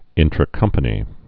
(ĭntrə-kŭmpə-nē)